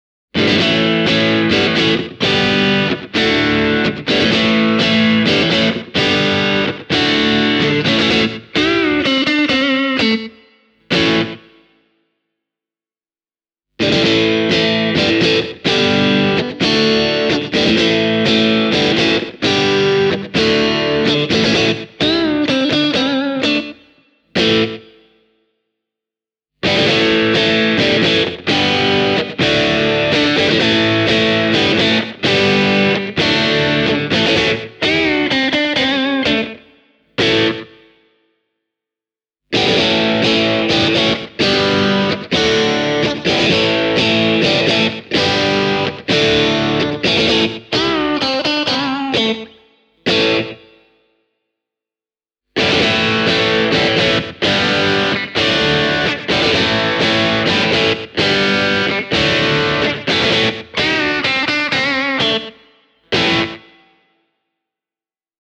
The neck and (reverse-wound) middle pickup use Alnico III magnets for a bright sound with a sharp attack and a fantastically dynamic response. The bridge pickup is wound a little hotter and comes loaded with more powerful Alnico V magnets for a more muscular tone.